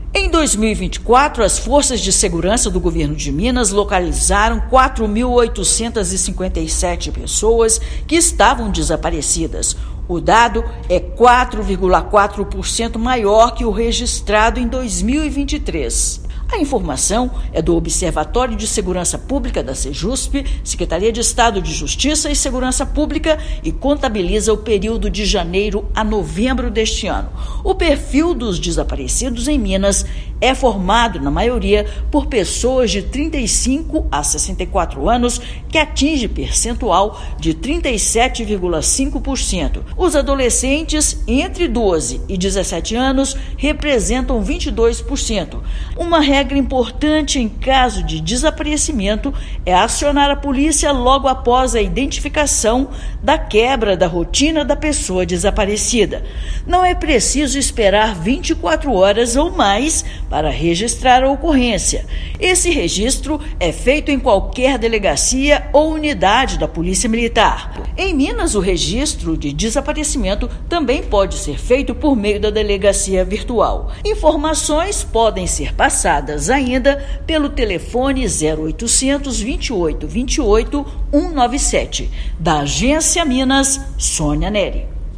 Dado divulgado pelo Observatório de Segurança Pública do Estado também indicou aumento na capital Belo Horizonte; saiba o que fazer e quando procurar ajuda. Ouça matéria de rádio.